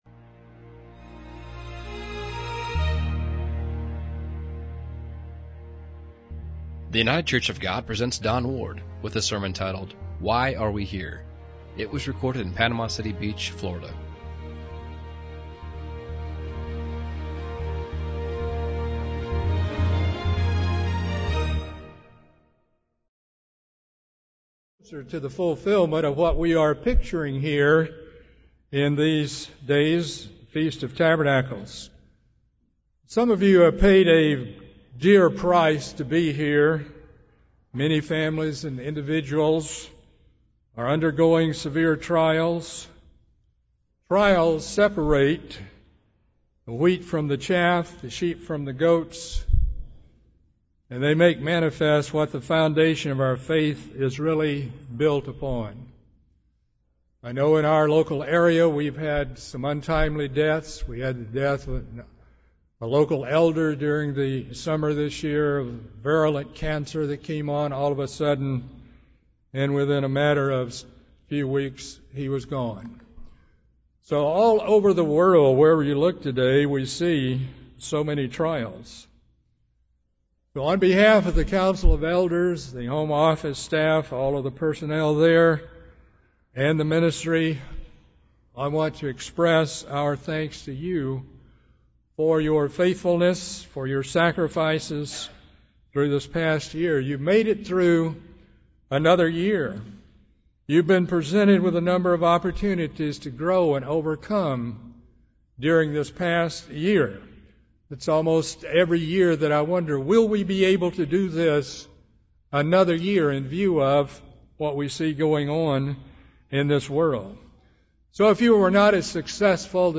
This sermon was given at the Panama City Beach, Florida 2013 Feast site.